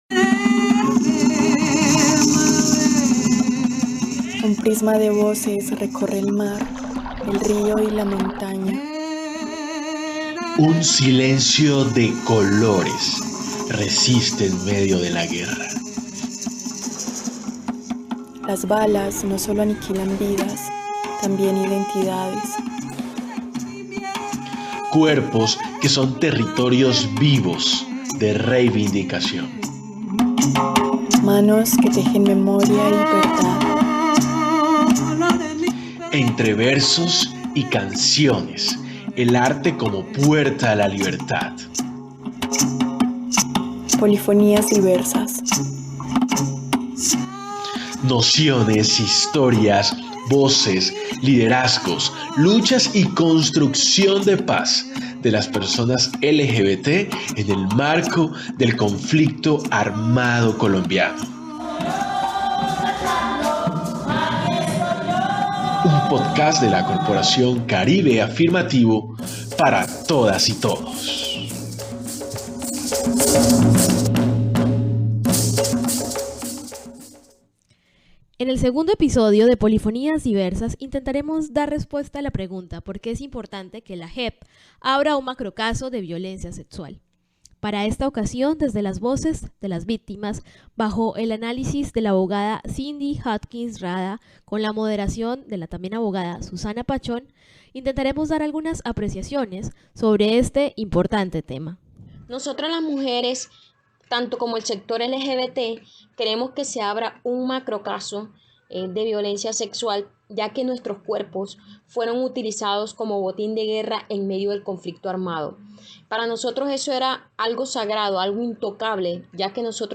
?> escuchar audio TIPO DE DOCUMENTO Entrevistas ALCANCE Y CONTENIDO Polifonías Diversas es un podcast producido por la corporación Caribe Afirmativo.